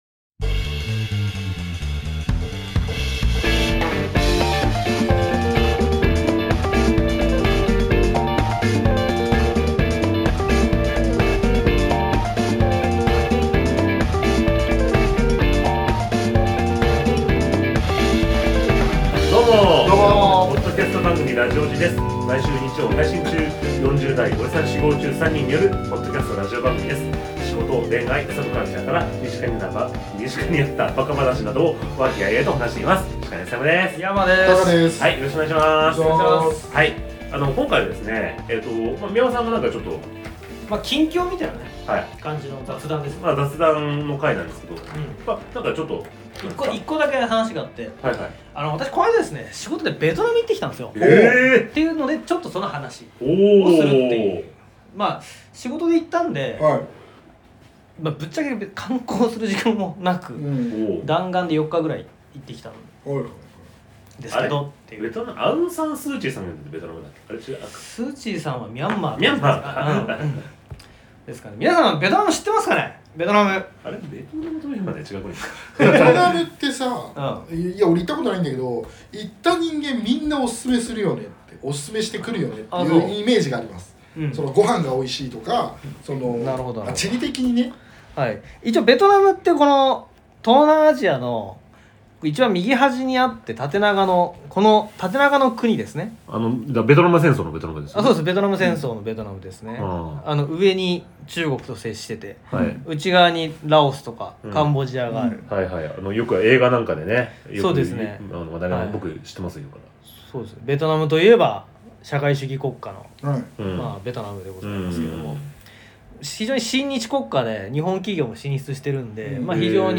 30代オジサン初心者3人によるポッドキャストラジオ。仕事、恋愛、サブカルから身近にあった馬鹿話等を和気あいあいと話しています。